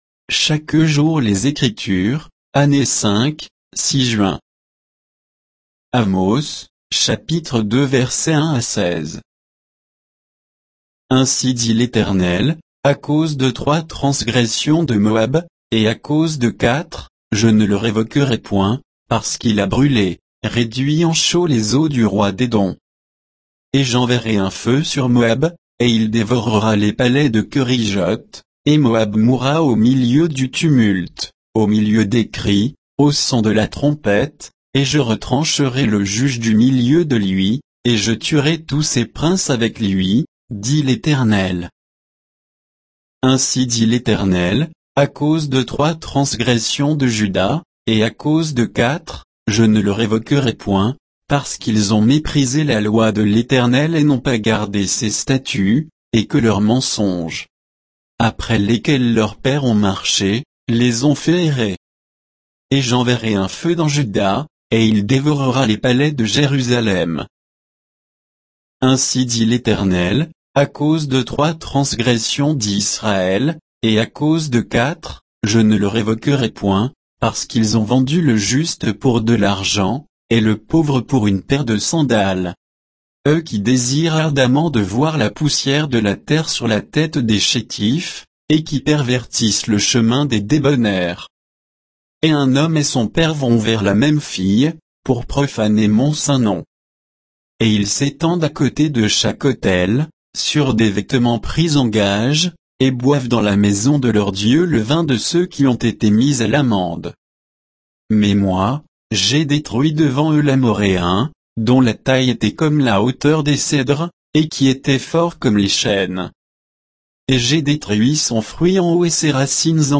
Méditation quoditienne de Chaque jour les Écritures sur Amos 2